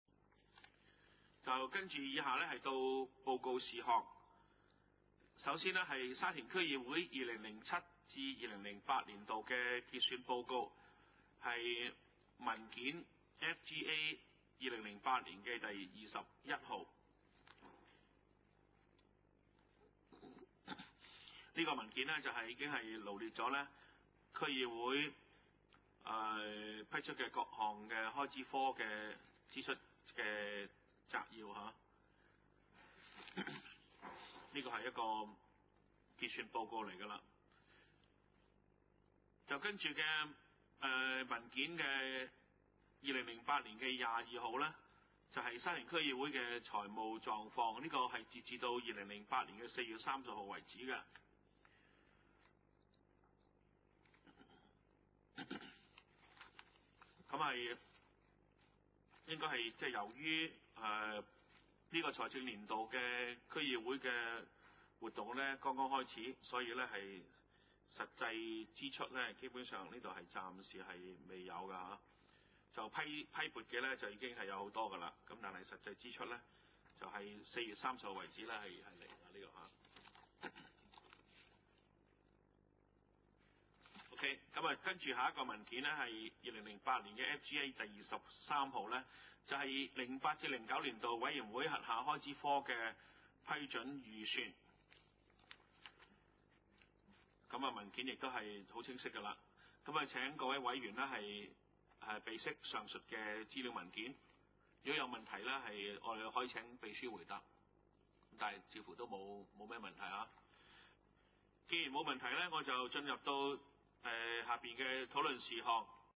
二零零八年第二次會議
: 沙田區議會會議室